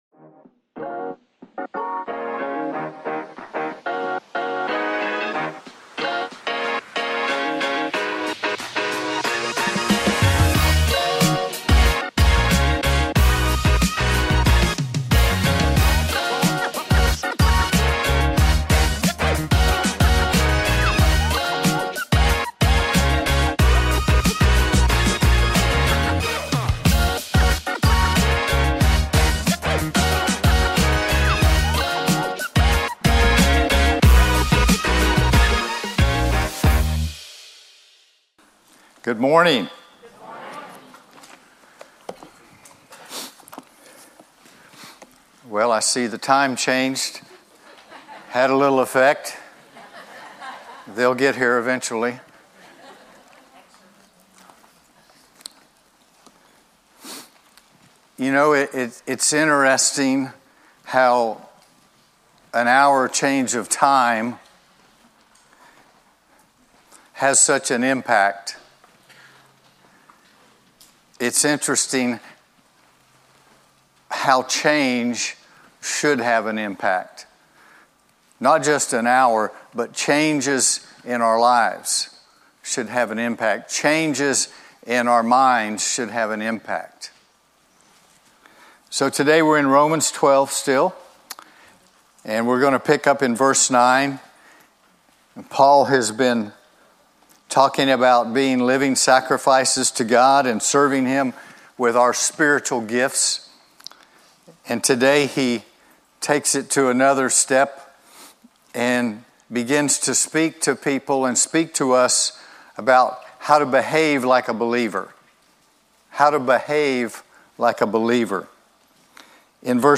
2026_03_08-Journey-Through-Romans-Part-20-Heartcry-Chapel-Sunday-Sermon.mp3